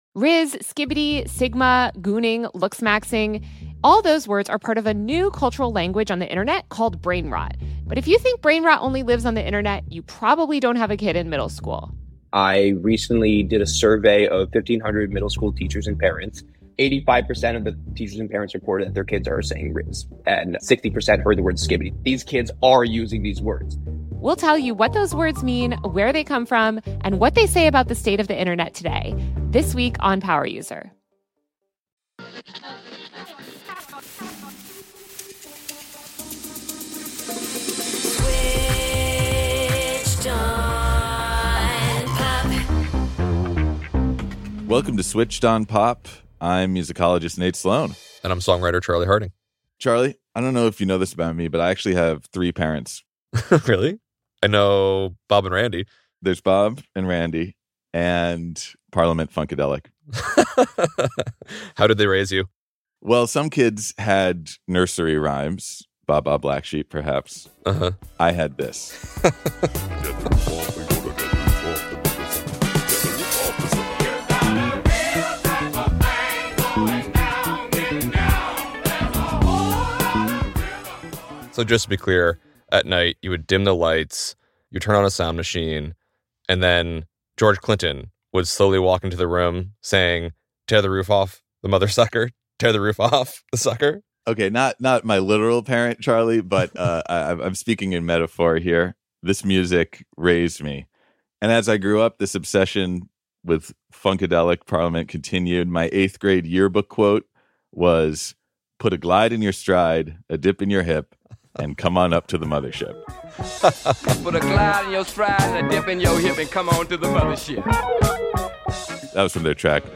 Over the course of his nearly six decade career, Collins has given up the funk in the iconic Parliament Funkadelic, helmed Bootsy’s Rubber Band, and lended his slaparific talents to songs from everybody from James Brown to Dee-Lite to Fatboy Slim. His new album, aptly titled Album of the Year #1 Funkateer, is on the way, so in celebration of his illustrious career, we invited Bootsy Collins – aka Casper the Funky Ghost – onto Switched on Pop to reminisce on some of his greatest hits.